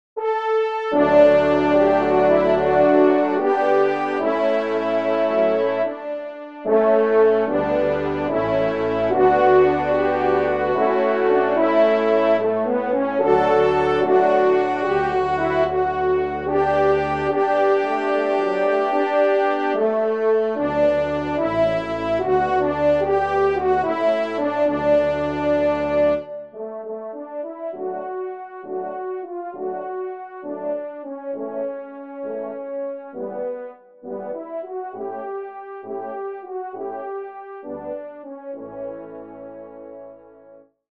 Genre : Divertissement pour Trompes ou Cors
ENSEMBLE